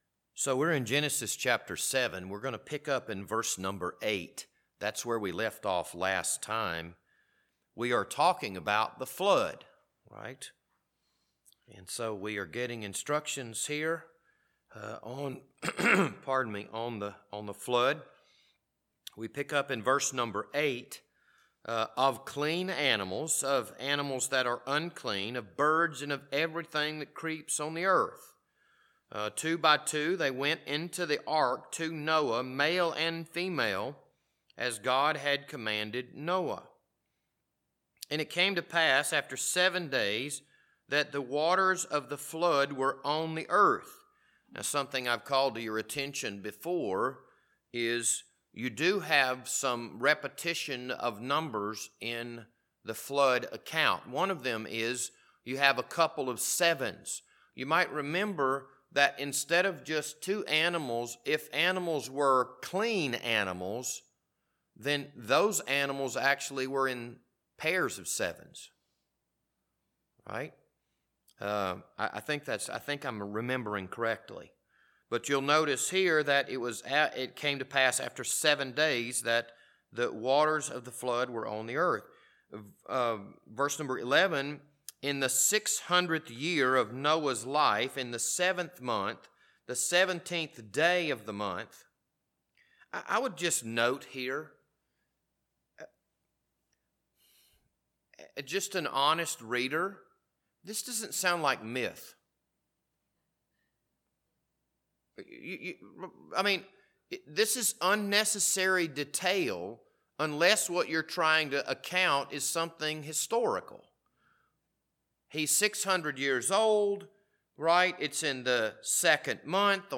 This Wednesday evening Bible study was recorded on July 13th, 2022.